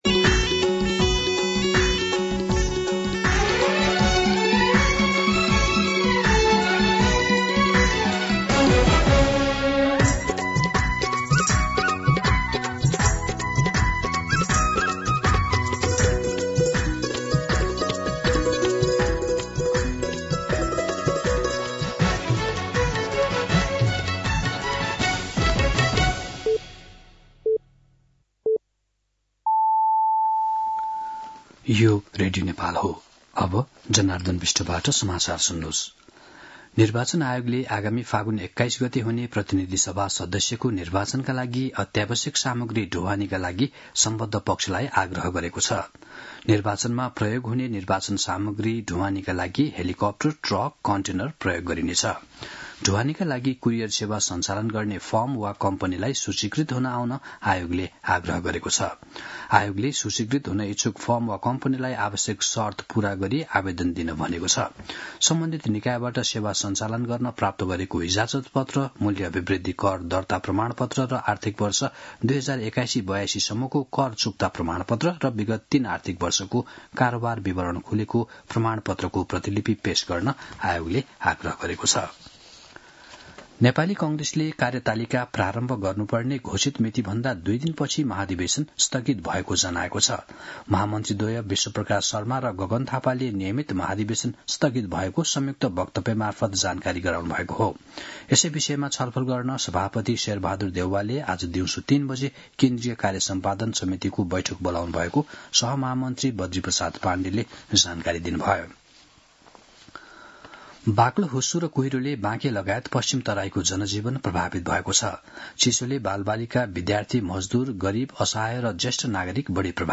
मध्यान्ह १२ बजेको नेपाली समाचार : १८ पुष , २०८२
12-pm-News-9-18.mp3